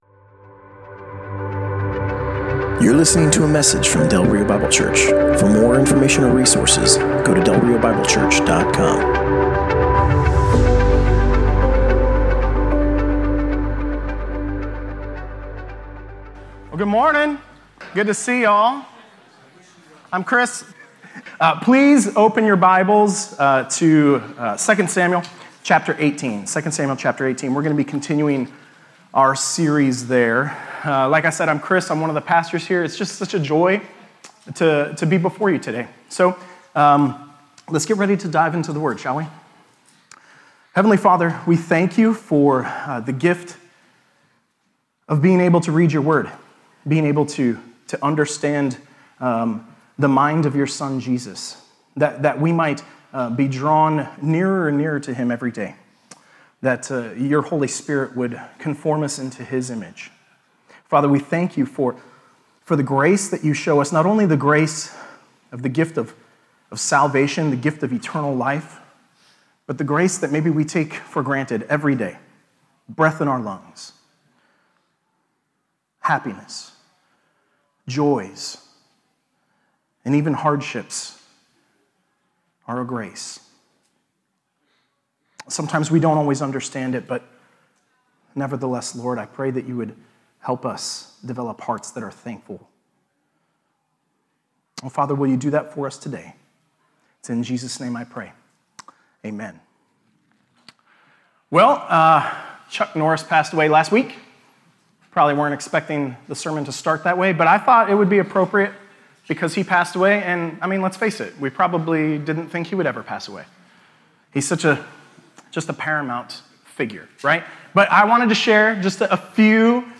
Passage: 2 Samuel 18: 19-19a Service Type: Sunday Morning